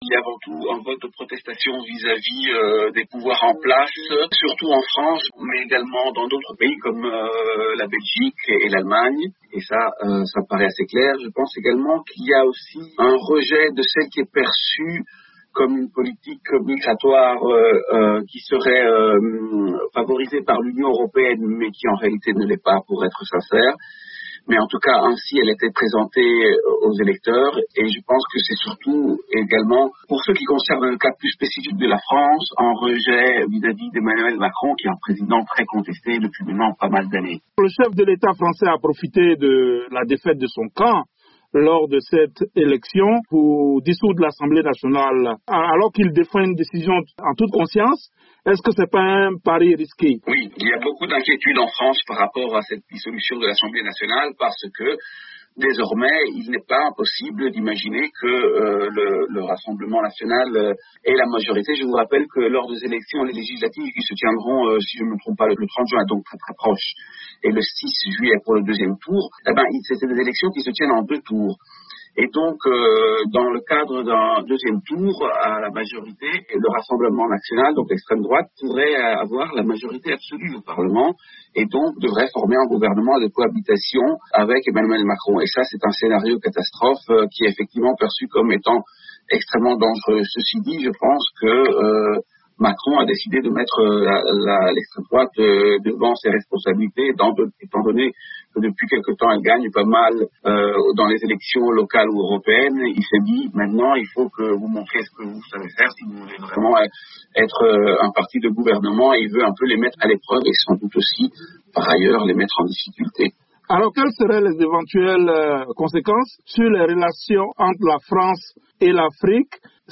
L'analyse